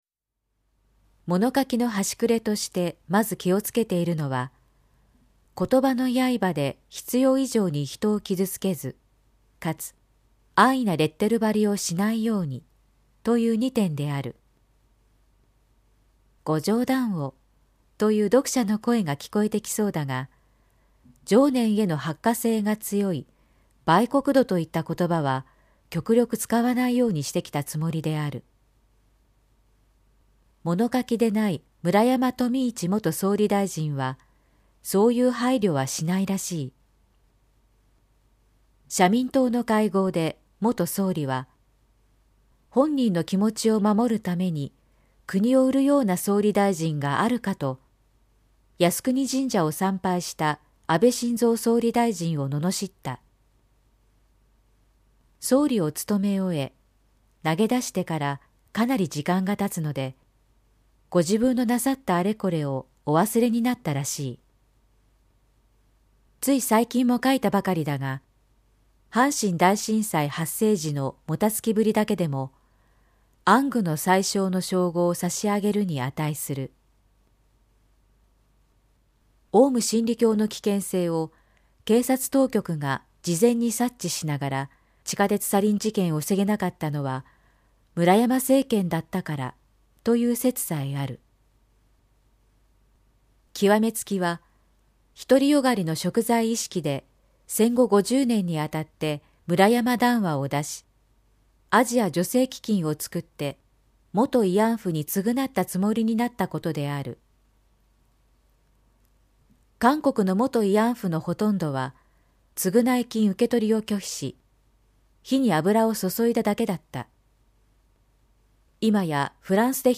産経新聞1面のコラム「産経抄」を、局アナnetメンバーが毎日音読してお届けします。